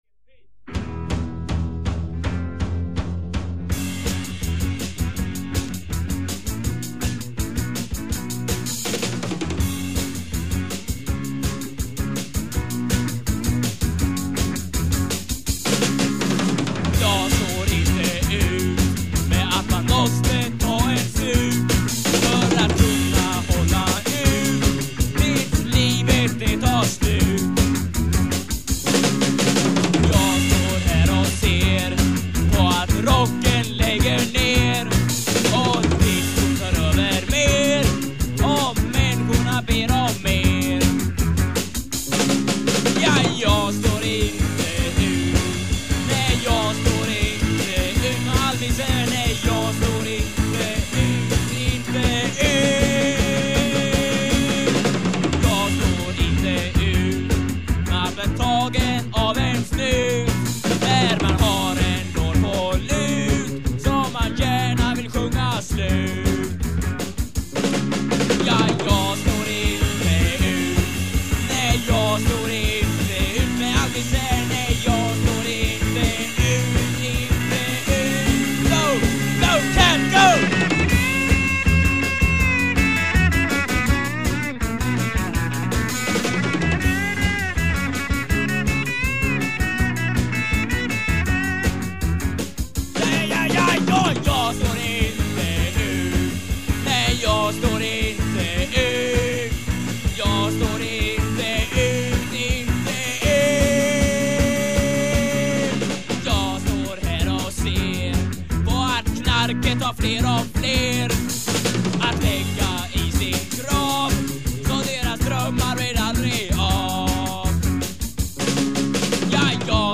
Guitar
Voice
Bass
Drums
Keyboards
Recorded in Blästadgården and at some basements in Vidingsjö